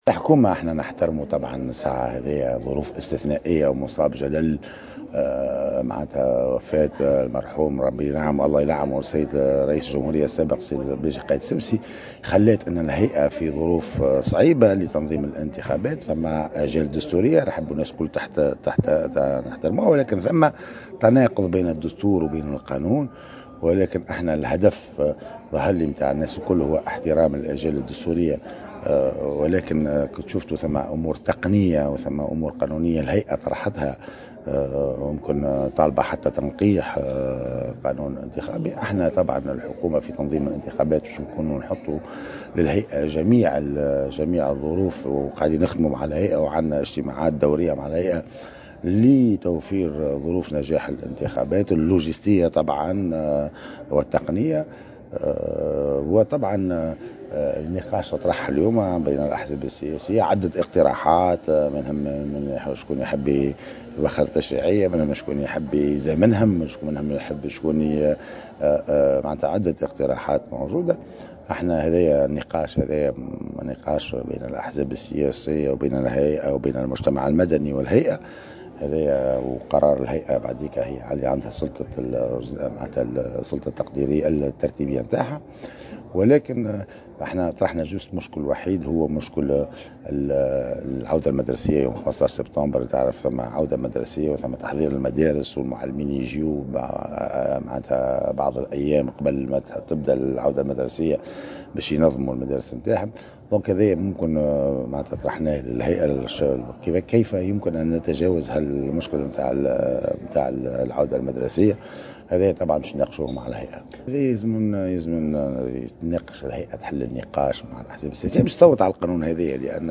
و في تصريح للجوهرة "أف أم" خلال اللقاء الموّسع الذي نظمته اليوم الهيئة العليا المستقلة بلإنتخابات حول رزنامة الإنتخابات الرئاسية السابقة لأوانها، أكدّ "المؤخر" تواصل النقاش بخصوص مختلف النقاط الخلافية بين الهيئة و الأحزاب السياسية و ممثلي مكونات المجتمع المدني، مشيرا إلى أن الهيئة طالبت بتعديل القانون الانتخابي، و الحكومة ستتفاعل مع ذلك في الوقت المناسب.